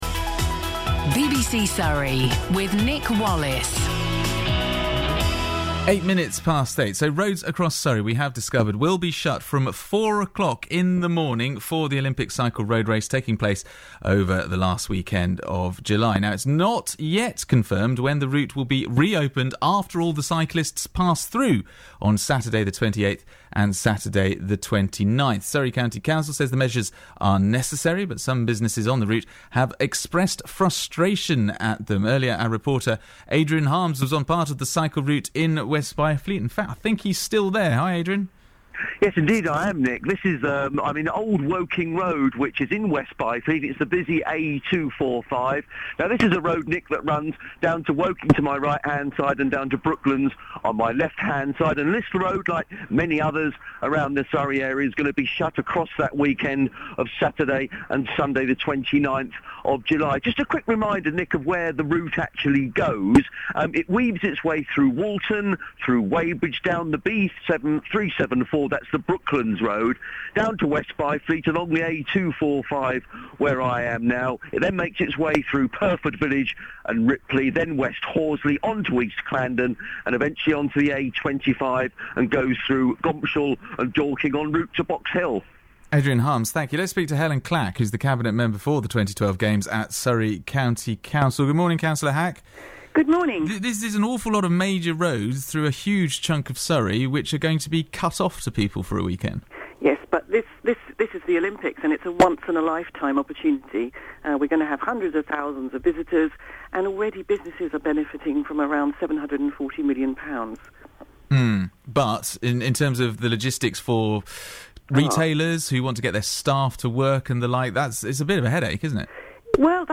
Helyn Clack interviewed on BBC Surrey
Cabinet Member for Community Services and the 2012 Games Helyn Clack was interviewed on BBC Surrey about preparing Surrey residents and businesses for the Olympic cycling road races this summer.